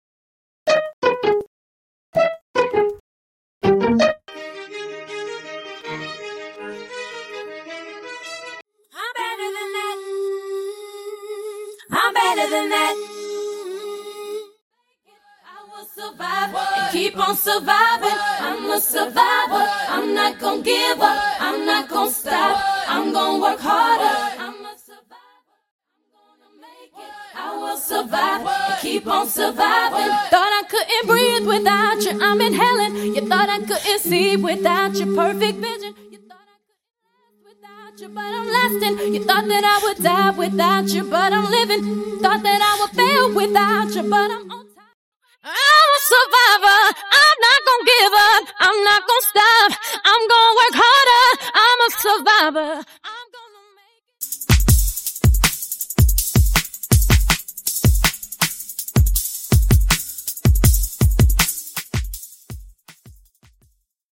Bass & Drums Stem
Strings Stem
Studio Backing Vocals Stem